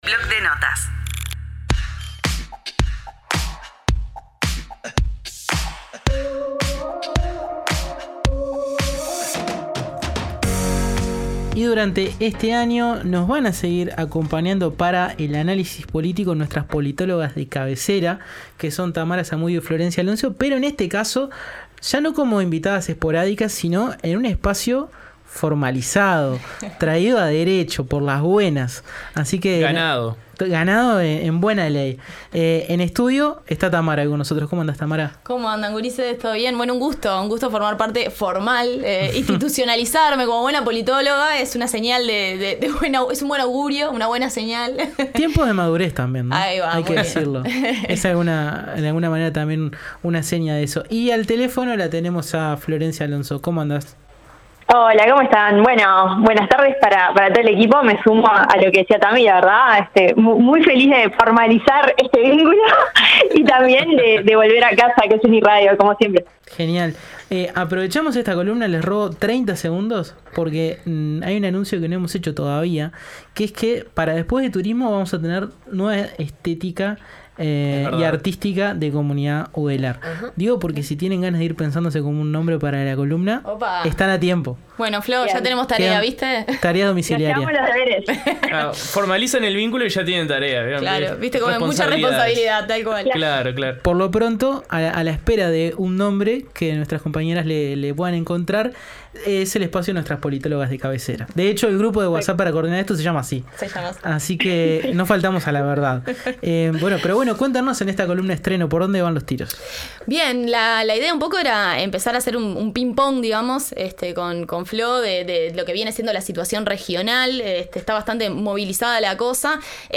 Comunidad Udelar, el periodístico de UNI Radio. Noticias, periodismo e investigación siempre desde una perspectiva universitaria.